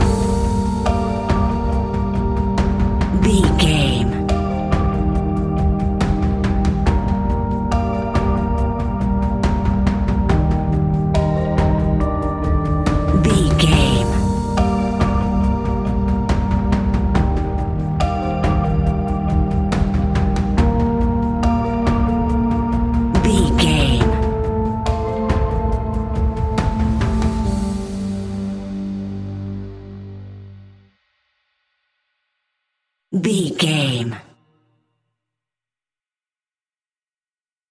Aeolian/Minor
ominous
dark
haunting
eerie
electric piano
synthesiser
drums
horror music
Horror Pads